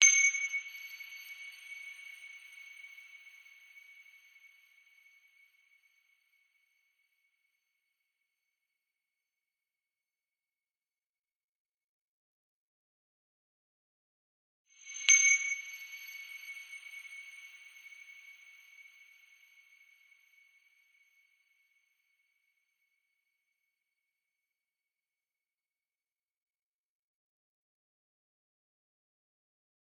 MAGShim_Sparkling Twinkle Bleep 1_EM
ambience ambient atmosphere atonal bar-chimes chime chime-tree cinematic sound effect free sound royalty free Nature